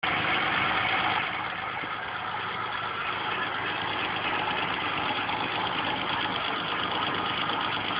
J'ai fait deux vidéos au téléphone portable (qualité minable mais bon...).
Au ralenti : téléphone collé au cache culbu. On entend un "crr crr crr crr crr " continu. On note aussi un "gnan gnan gnan gnan", ça vient de l'alternateur, ça je le savais.
(Sur le son au ralenti, le tic tic tic vient des culbuteurs (les injecteurs font aussi tic tic tic mais de l'autre côté :D), le cognement ressemble à une bieille)
ralenti.wav